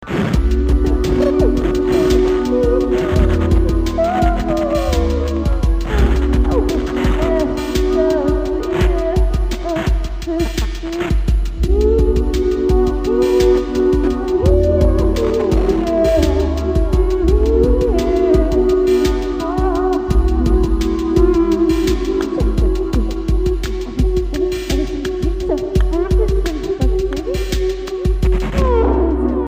has got no words at all